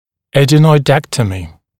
[ˌædənɔɪ’dektəmɪ][ˌэдэной’дэктэми]аденоидэктомия, удаление аденоидов